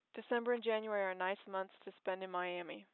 Samples for 2 male and 2 females chosen for their many high-frequency sounds:
sx134_1: Female (DR2/FPAS0)
All files use MFCC-based BWE. The "dynamic" version are based on I=128, J=4, Nmin=500, l=3, and Tau=4 (40ms), i.e., narrowband representation has dim 40 (frames at time -120, -80, -40, and 0 ms)